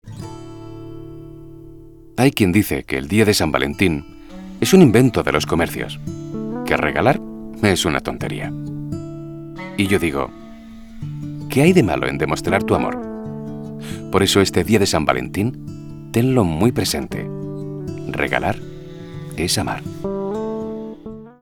Neuman U87ai TlAudio 5051 Focusrite soundcard Protols 12,8,3
Sprechprobe: Werbung (Muttersprache):
Dubbing actor with 26 years of experience.